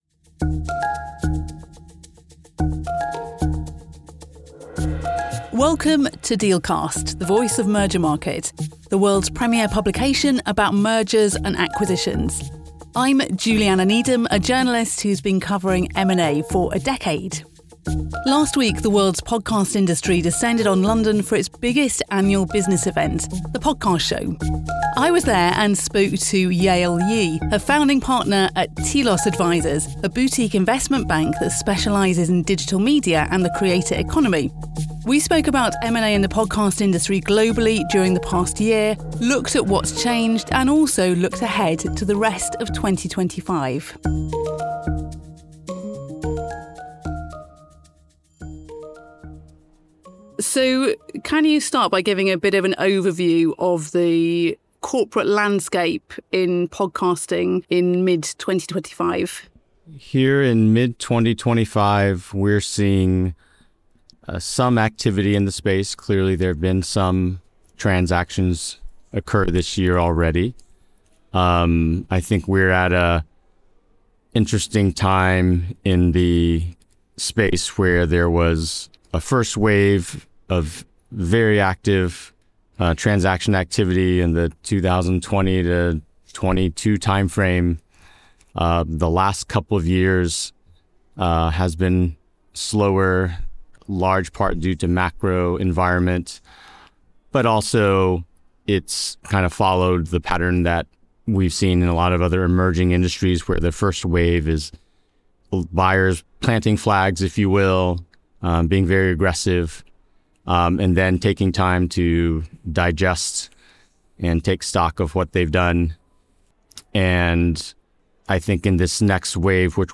speaks live from the Deal Makers Retreat in Marbella, Spain, talking about the importance of personal branding when negotiating business deals